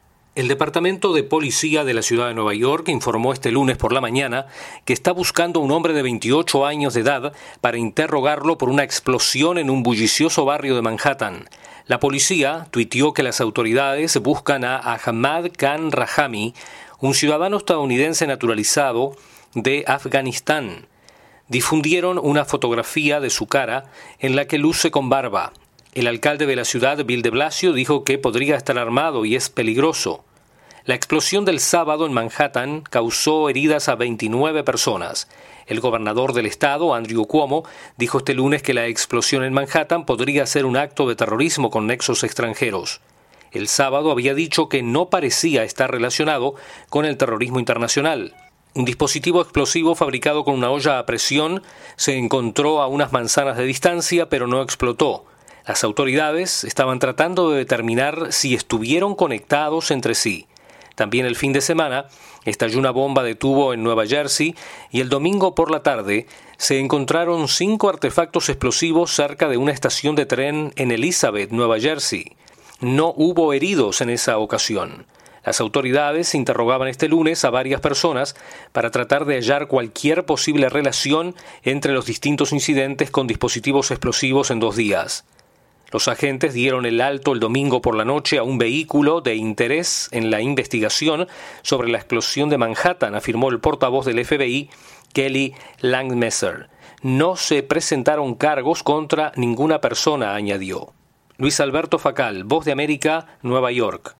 La pollicía busca a un hombre de 28 años por la explosión del sábado en Manhattan. Desde Nueva York informa